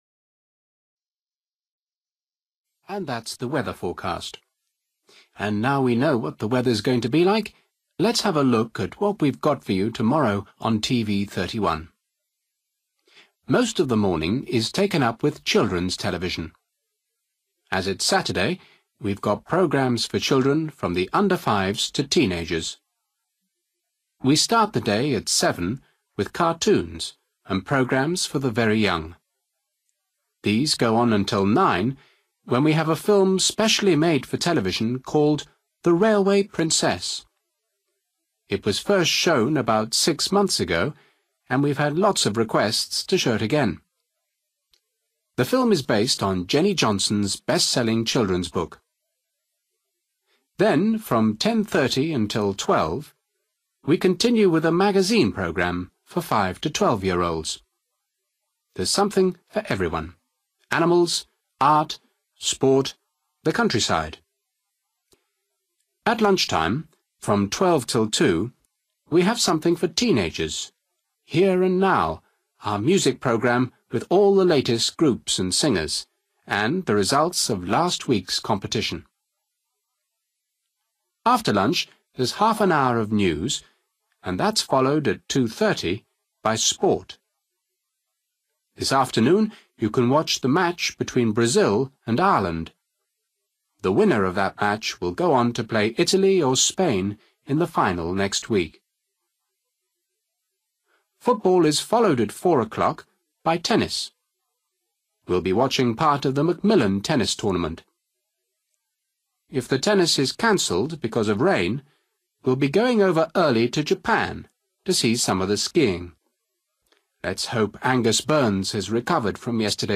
You will hear an announcement about what’s on television tomorrow.